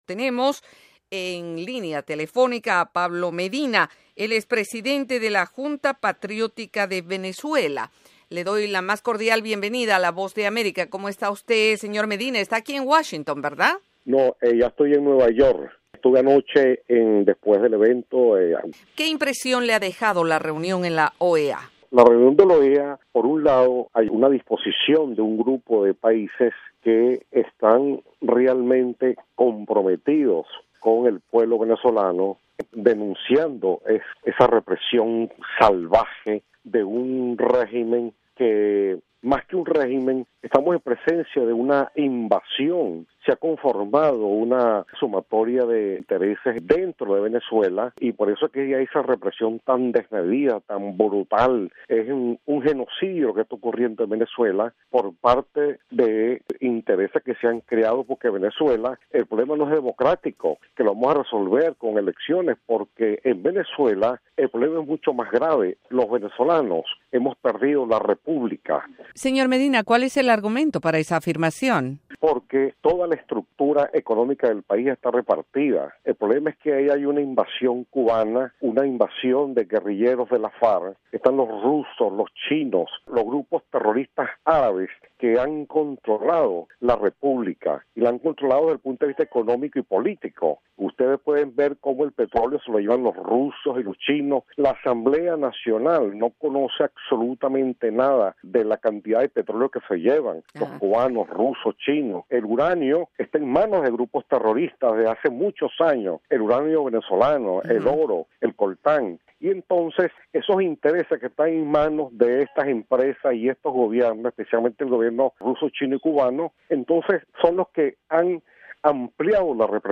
Pablo Medina es el presidente de la Junta Patriótica de Venezuela y viajó desde Caracas hacia Washington DC y Nueva York para presentar una hoja de ruta para una salida a la crisis en su país y en entrevista con la Voz de América analiza la reunión de la OEA y el panorama actual